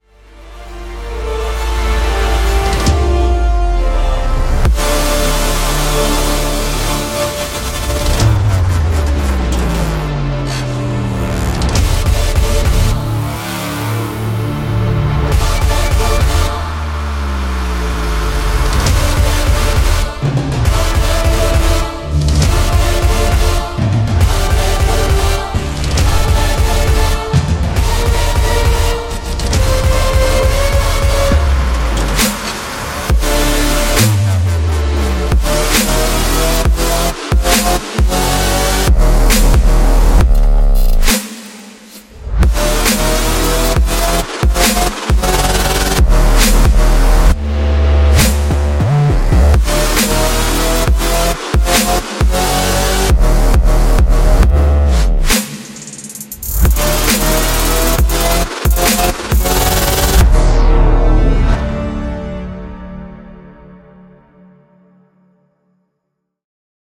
现在，他带来了同名的样本，展示了他毫不妥协的声音的最佳元素——探索令人惊叹的贝司、令人惊叹的管弦乐旋律